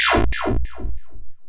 bolt.wav